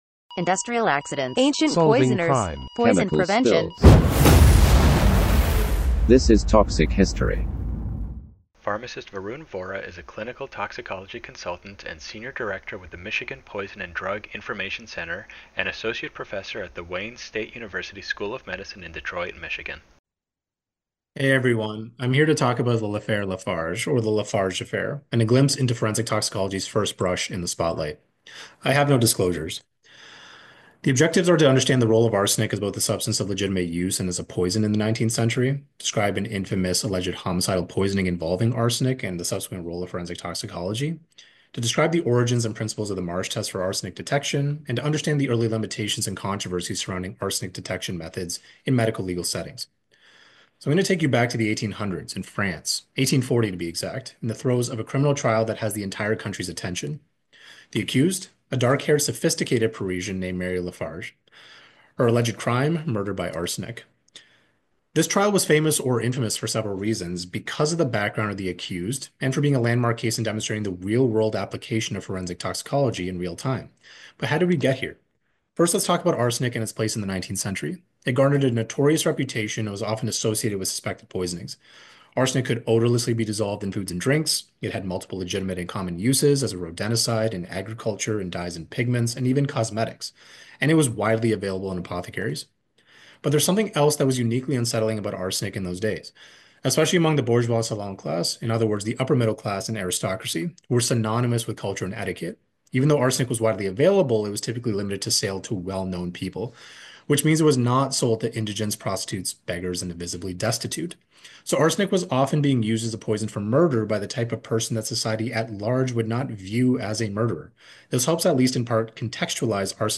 Toxic History! is a narrative medicine lecture series where medical experts tell you stories from the history of poison.